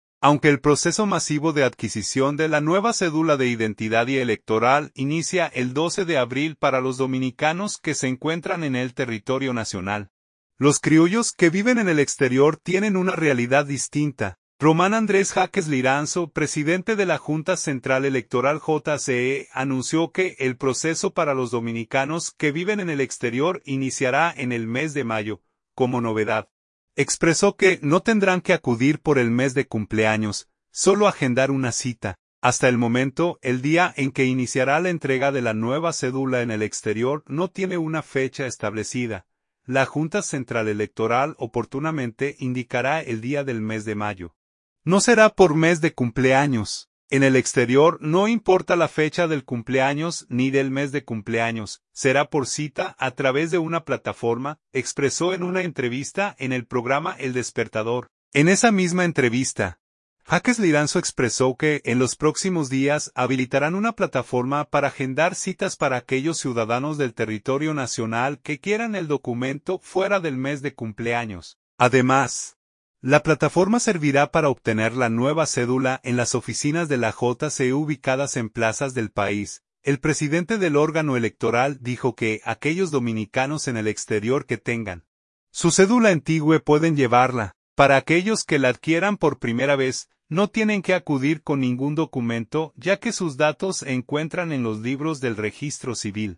“La Junta Central Electoral oportunamente indicará el día del mes de mayo; no será por mes de cumpleaños. En el exterior no importa la fecha del cumpleaños ni del mes de cumpleaños; será por cita a través de una plataforma”, expresó en una entrevista en el programa El Despertador.